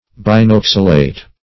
Search Result for " binoxalate" : The Collaborative International Dictionary of English v.0.48: Binoxalate \Bin*ox"a*late\, n. [Pref. bin- + oxalate.]
binoxalate.mp3